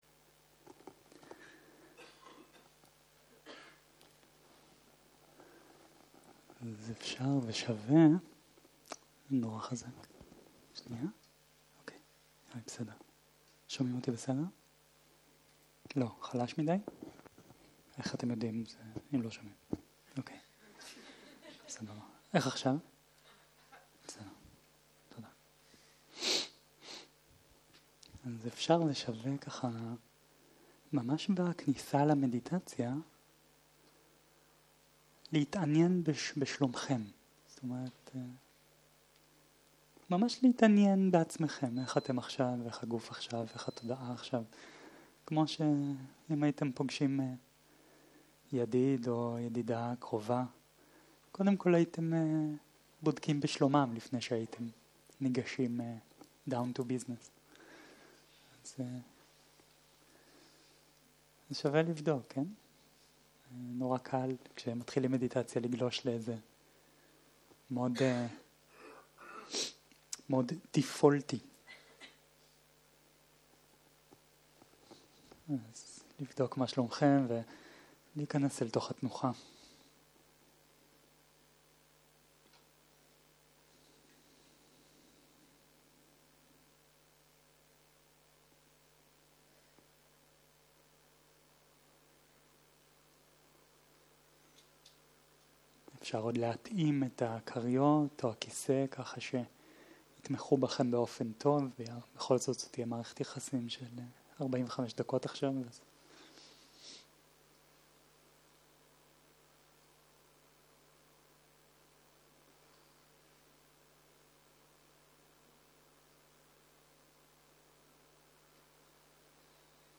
יום 3 - ערב - מדיטציה מונחית - תרגול עם השתוקקות והיאחזות - הקלטה 7